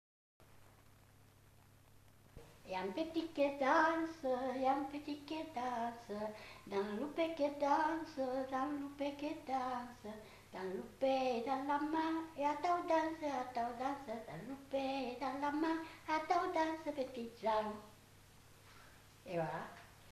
Aire culturelle : Gabardan
Genre : chant
Effectif : 1
Type de voix : voix de femme
Production du son : chanté
Classification : danses